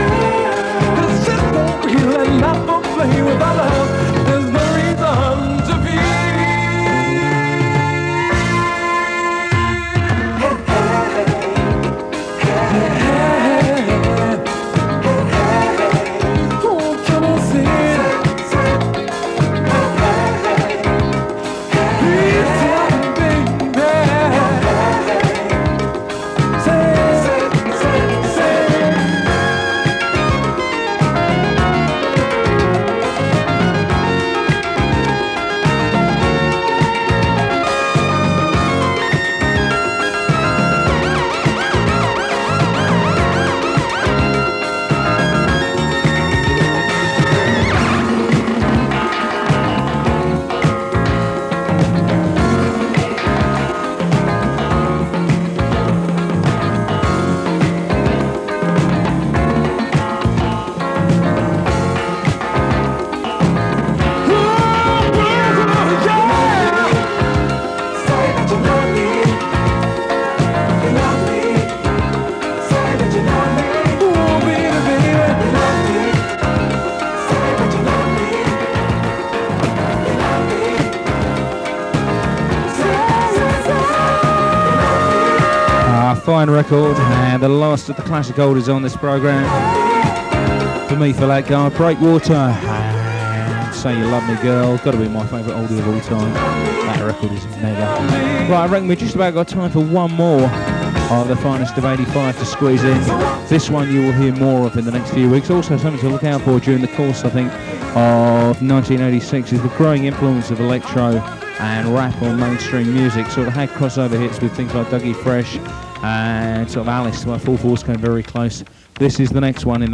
The mic is a bit distorted in places. Recorded from 90.4MHz in mono in Ickenham. 90MB 66mins